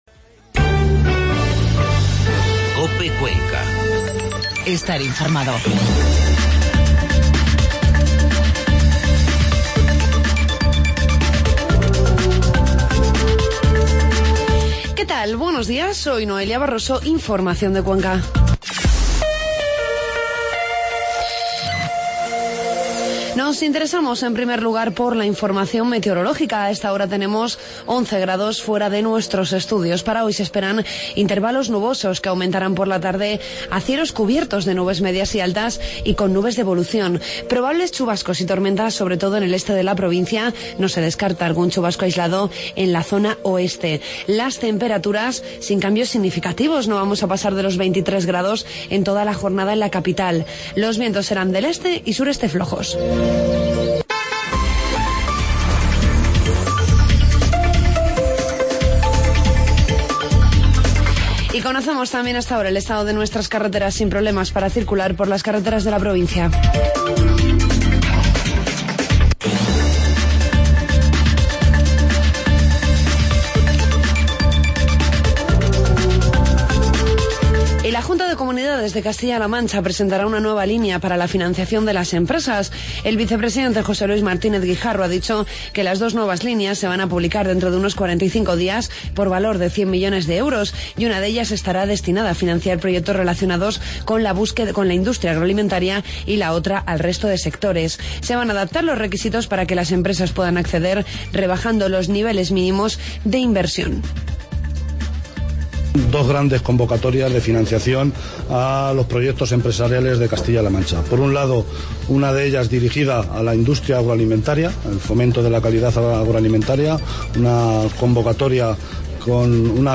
Informativo matinal COPE Cuenca martes 29 de septiembre